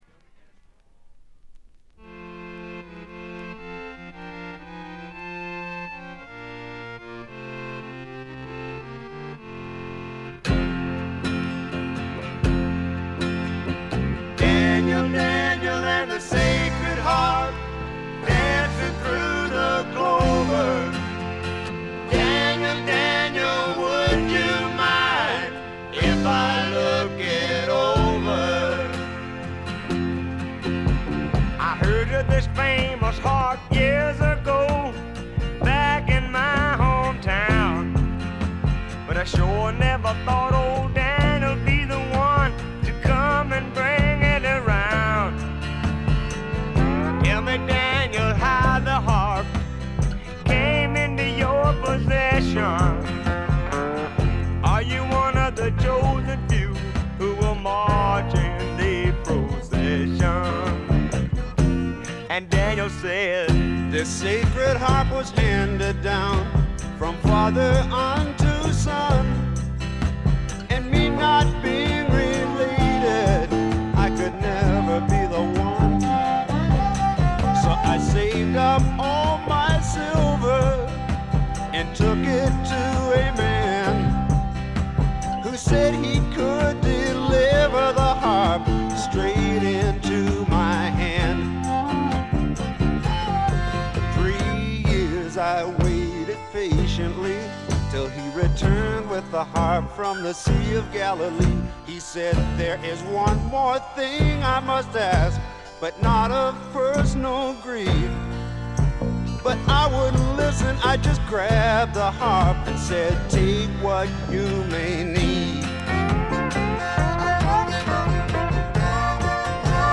ディスク：部分試聴ですがほとんどノイズ感無し。
試聴曲は現品からの取り込み音源です。